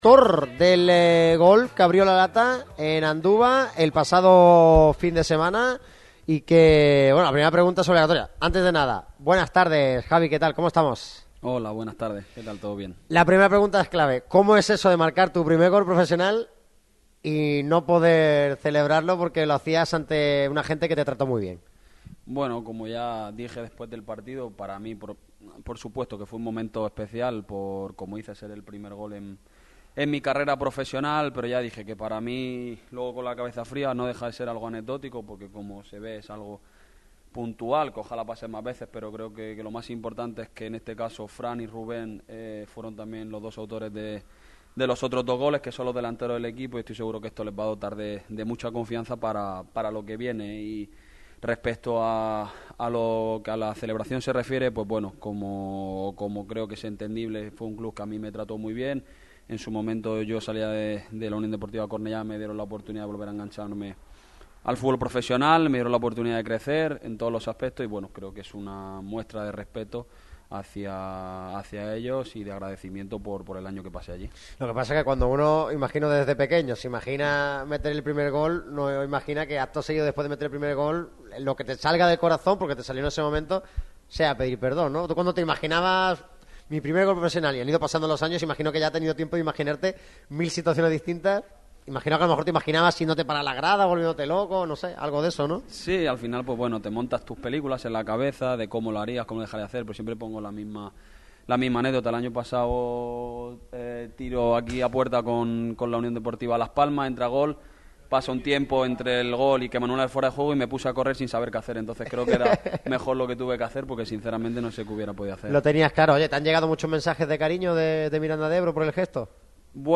El lateral izquierdo del Málaga CF, Javi Jiménez, pasó por el micrófono rojo de Radio MARCA Málaga, hoy desde el Estadio de La Rosaleda. El jienense trató todos los temas candentes tras la victoria en Anduva y las tres primeras jornadas de LaLiga SmartBank.